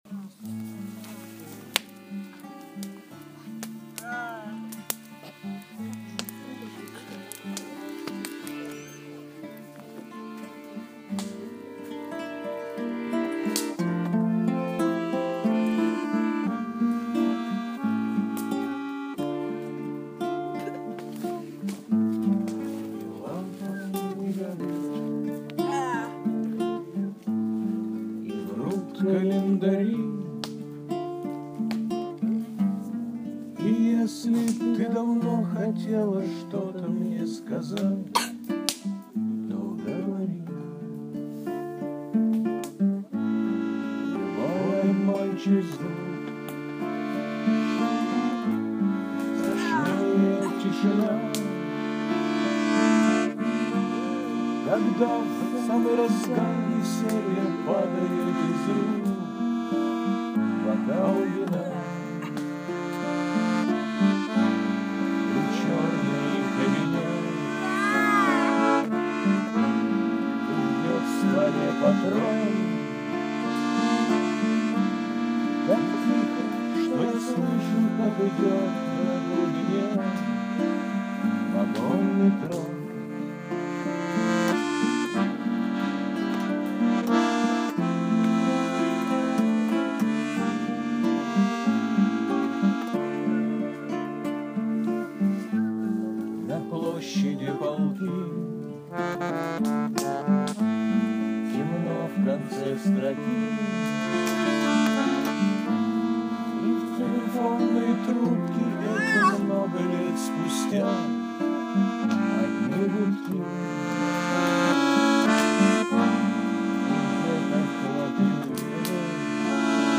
Песни у костра (лагерь-2014)